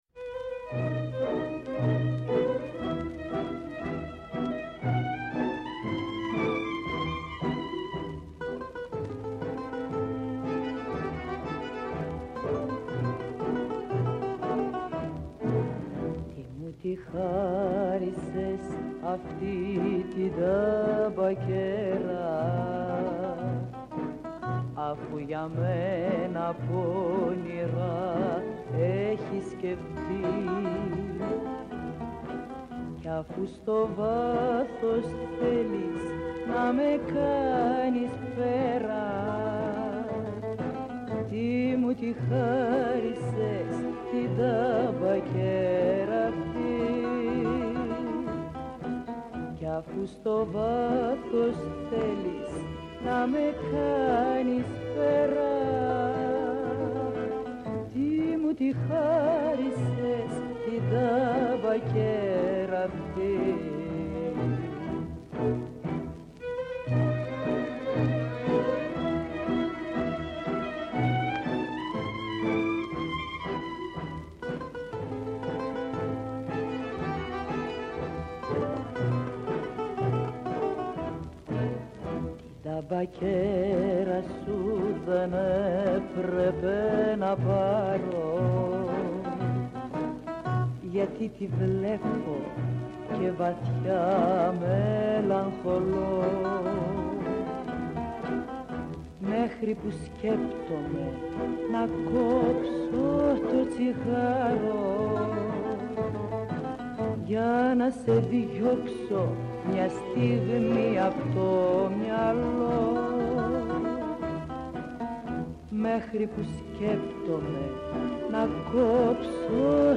Η Σωστή Ώρα στο Πρώτο Πρόγραμμα της Ελληνικής Ραδιοφωνίας